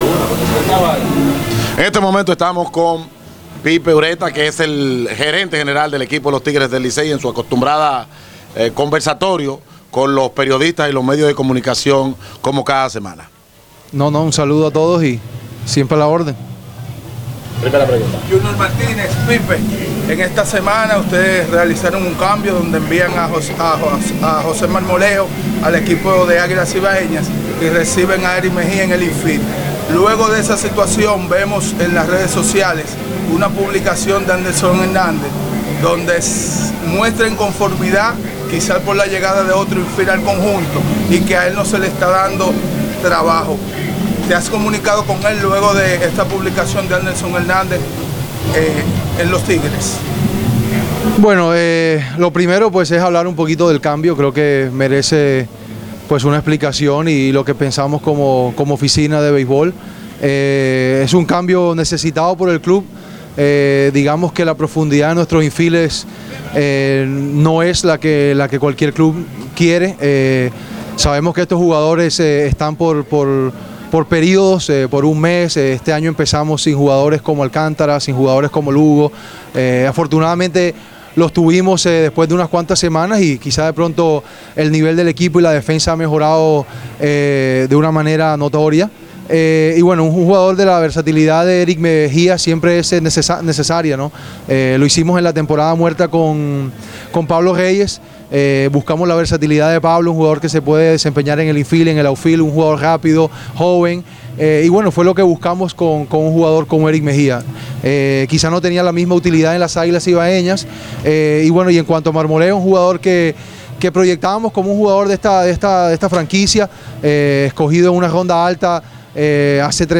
Durante la acostumbrada rueda de prensa semanal, que ofreció este martes 13 antes del partido contra el Escogido, afirmó que Hanley Ramírez comenzará como bateador designado, pero que podrá ver acción en la primera base, porque necesita jugar defensa y así el jugador lo quiere.